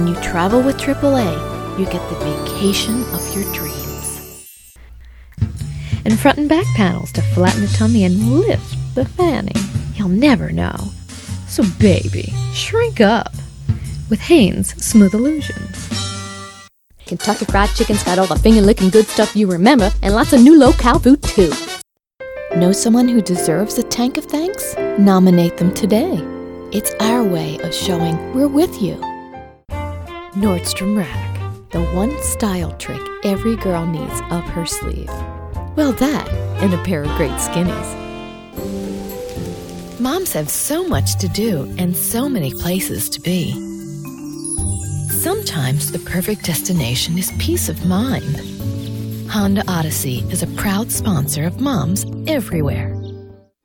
US American, female voice
mid-atlantic
Sprechprobe: Werbung (Muttersprache):